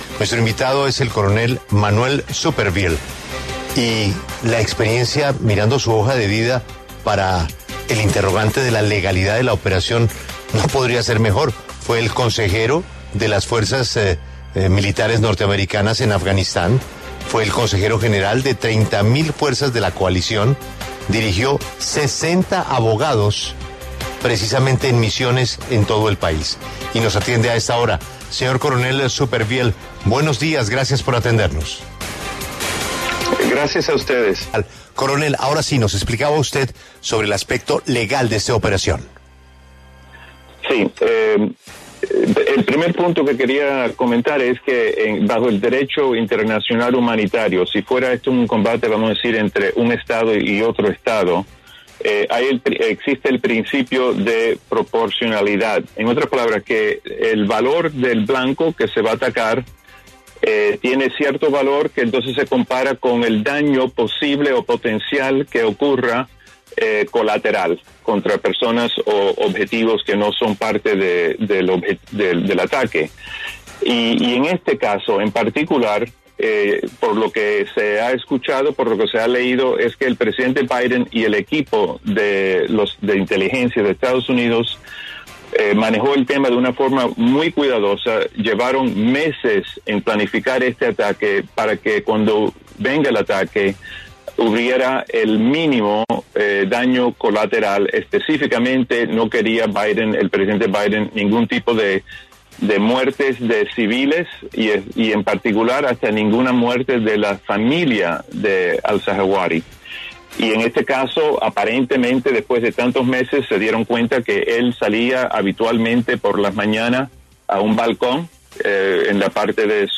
A propósito de la operación contra Ayman al-Zawahiri, líder de Al Qaeda que murió tras un ataque con drones en Kabul, La W conversó con un experto sobre el impacto que tuvo este operativo y su legalidad.